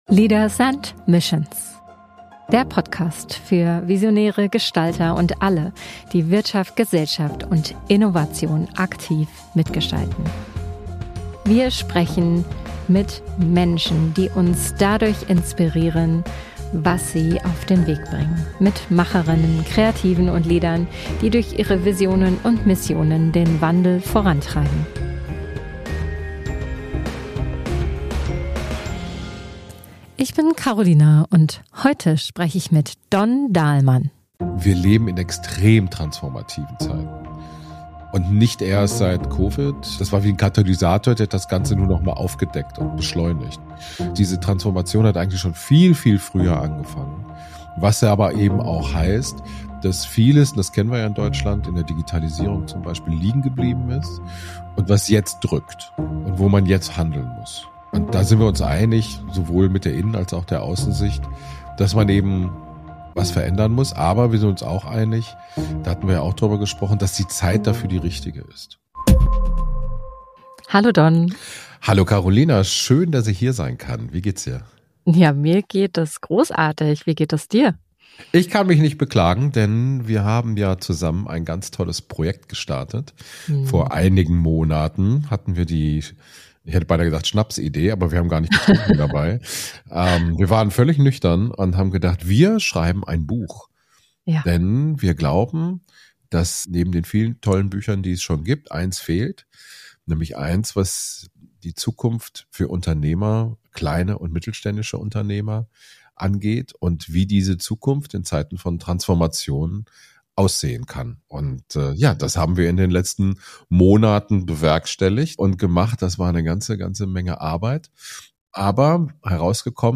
Ein Gespräch über Leadership, Verantwortung und warum „Conscious Business“ mehr ist...